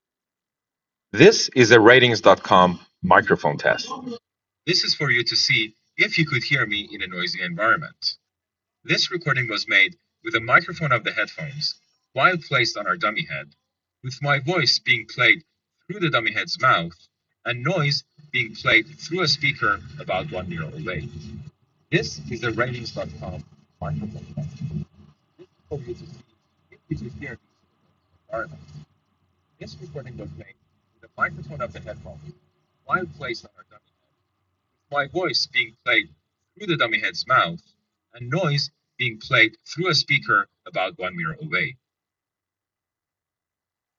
Or, if you want to hear how they handle background noise, you can listen to our recordings taken in